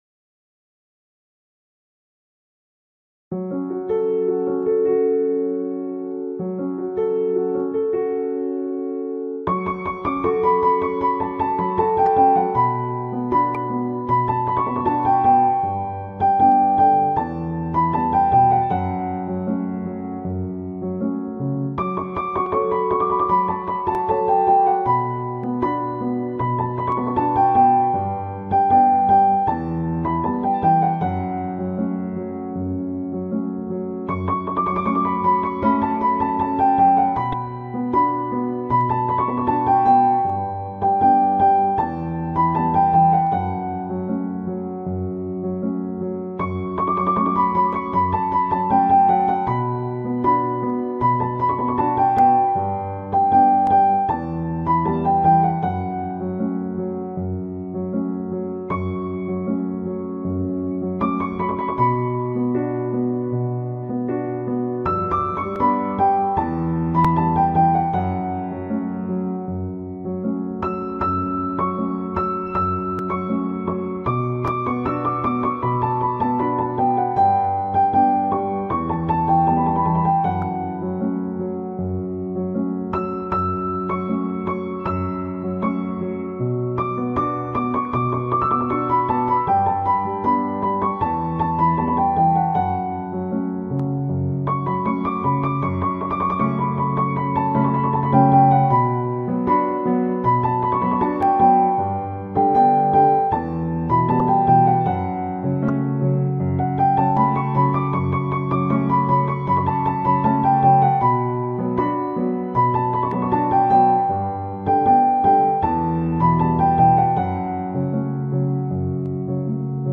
ترکیه ای غمگین
آهنگ بی کلام معروف ترکی